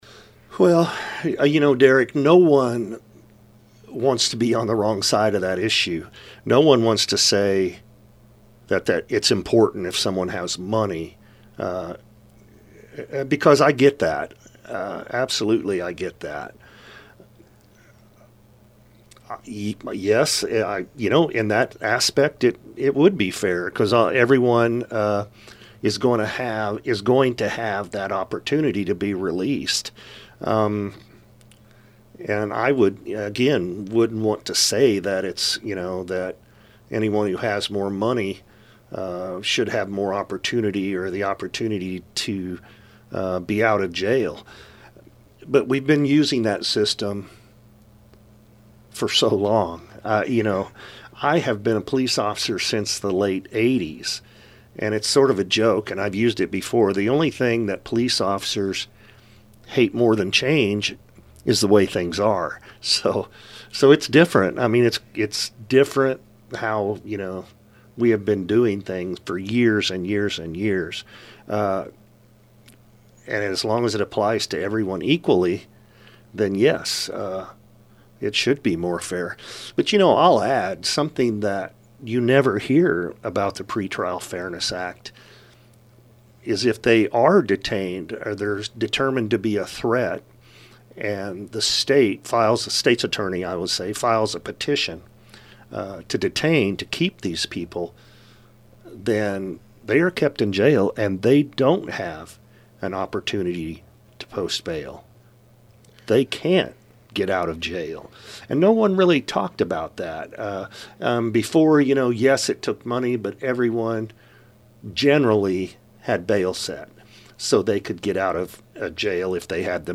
SAFE-T Act Details Explained In Interview With Effingham County Sheriff Paul Kuhns
safe-t-act-interview-part-2.mp3